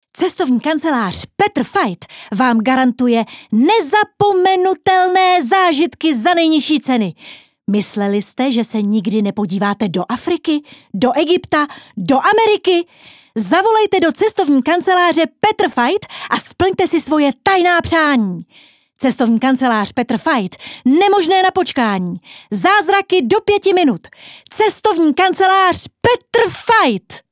Rozhlasová reklama: